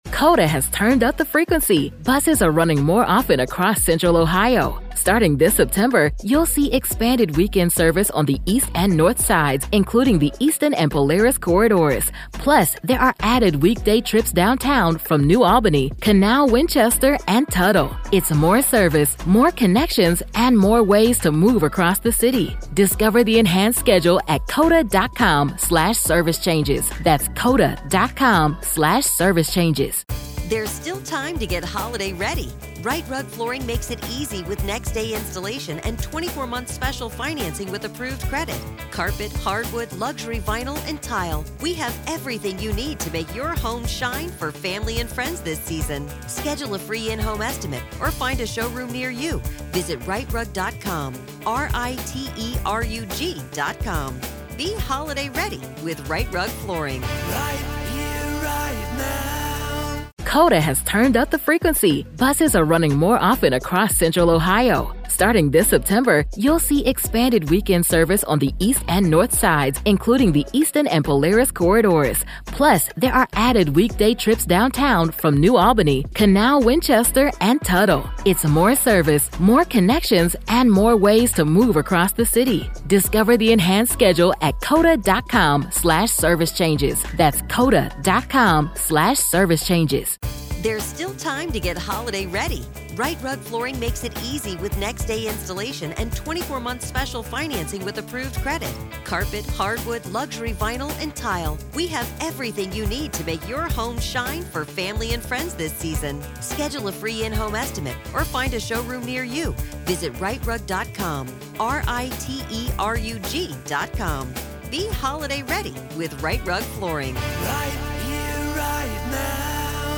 This interview digs into why these inconsistencies matter — not emotionally, but legally.